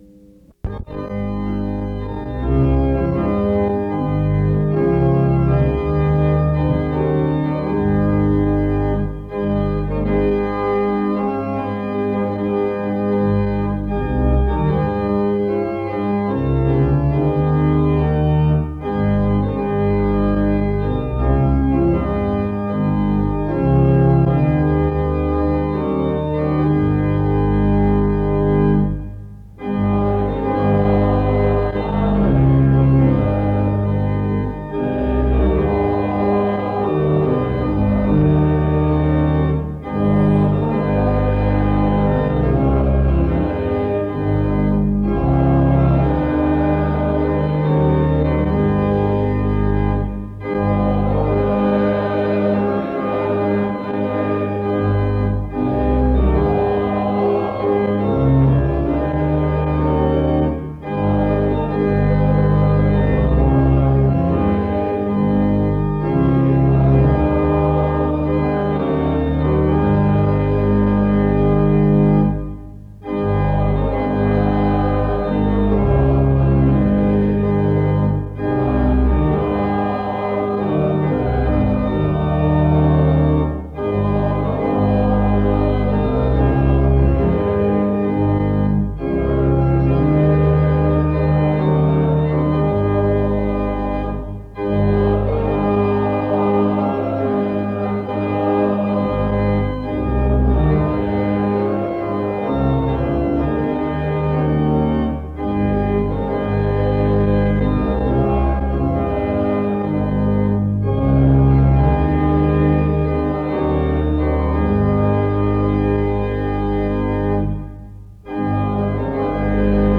The service starts with music from 0:00-3:38. A responsive reading takes place from 4:00-5:41. A prayer is offered from 5:42-8:56.
He shares what he believes makes Southeastern a unique institution. Music closes the service from 19:57-24:21.